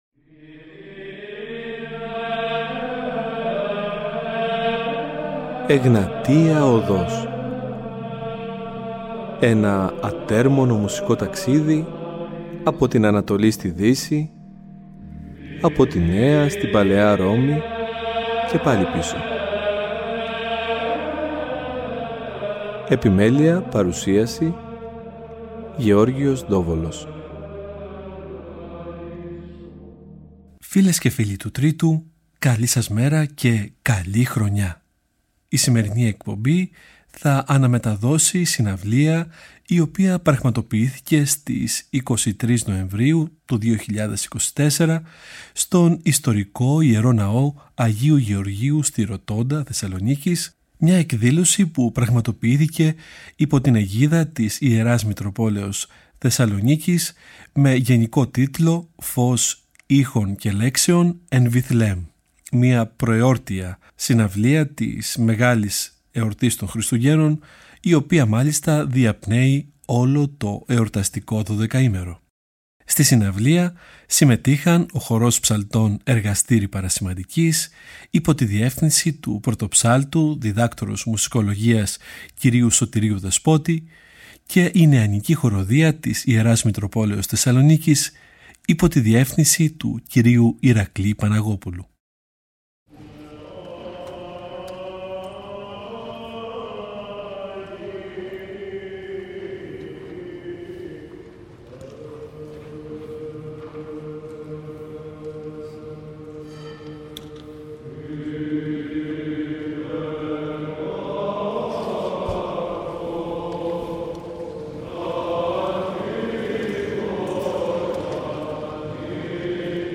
Με τον γενικό τίτλο «Φως Ήχων και Λέξεων» πραγματοποιήθηκε στην Θεσσαλονίκη μια ξεχωριστή εκδήλωση στον ιστορικό Ναό του Αγίου Γεωργίου στη Ροτόντα.
Βυζαντινη Εκκλησιαστικη Μουσικη